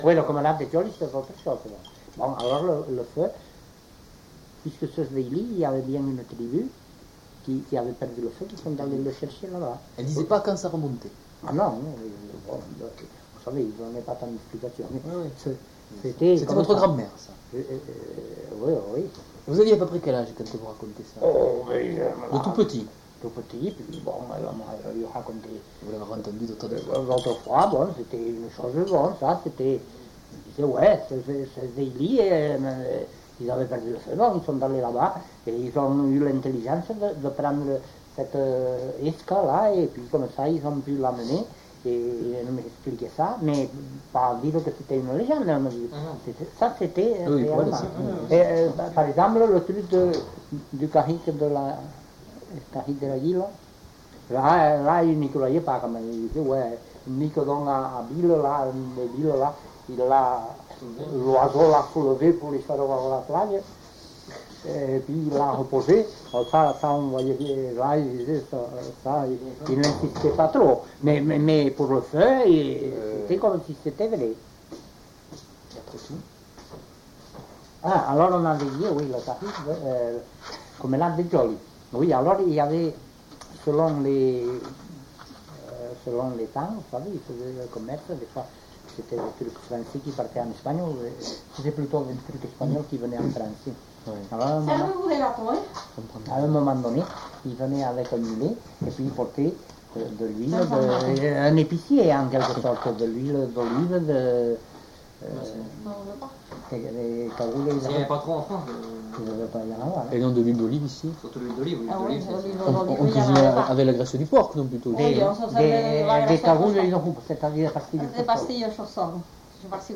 Aire culturelle : Couserans
Genre : conte-légende-récit
Type de voix : voix d'homme
Production du son : parlé
Classification : récit légendaire